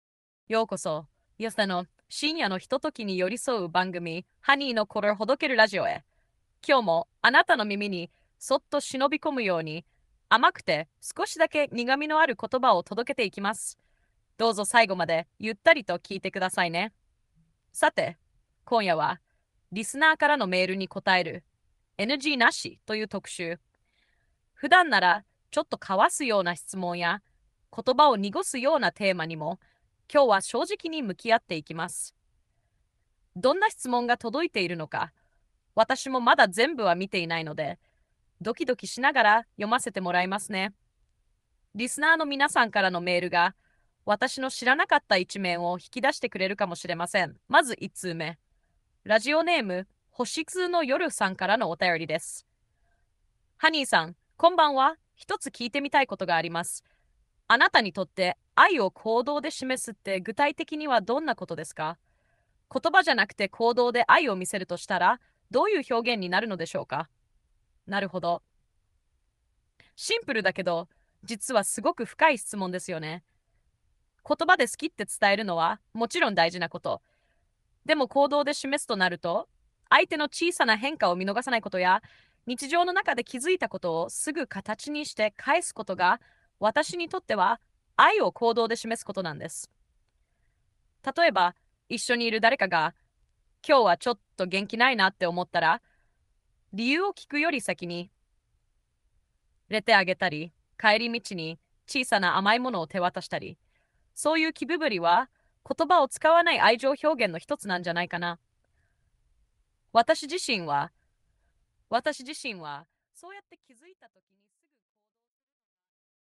ハニーの"一般向け"の疑似ラジオ
音声は「Maple」を選択。
テキスト読み上げのイントネーションも相俟って、「日本語をよく知っているのに喋り慣れていない外国人」感もあるというか。
honey_radio.mp3